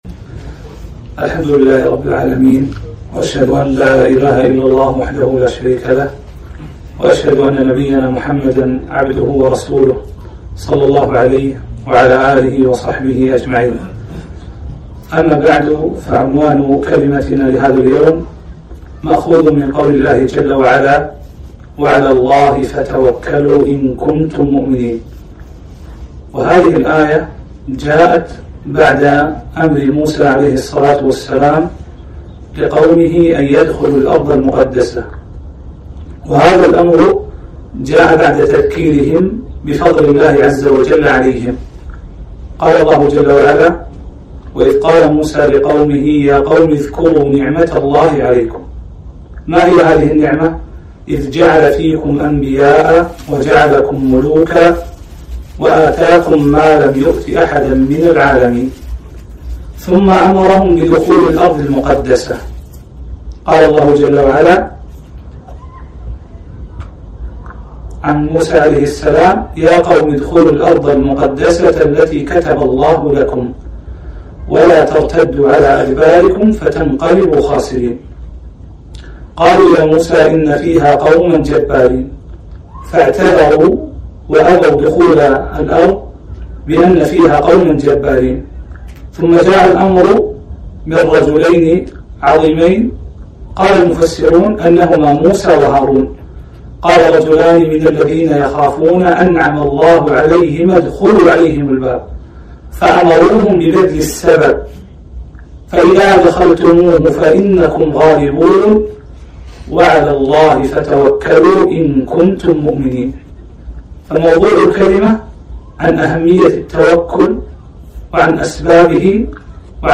كلمة - (وعلى الله فتوكلوا إن كنتم مؤمنين)